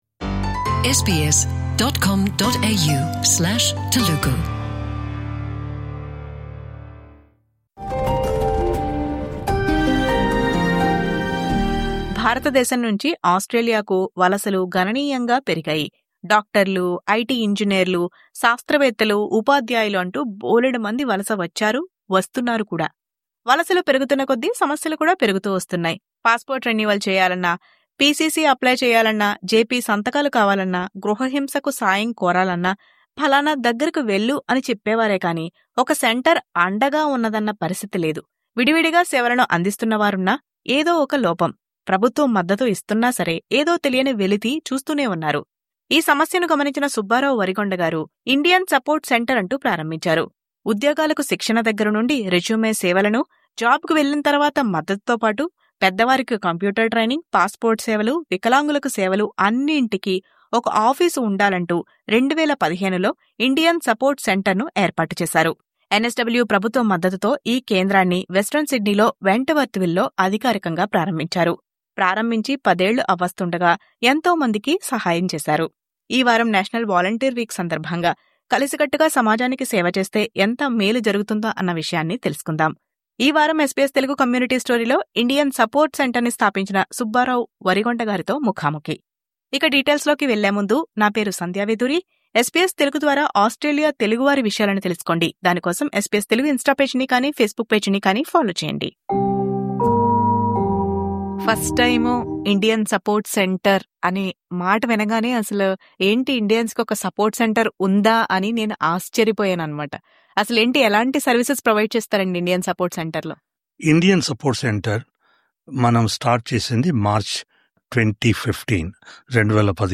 ఈ వారం SBS తెలుగు కమ్యూనిటీ స్టోరీలో ఆయనతో ప్రత్యేక ముఖాముఖి.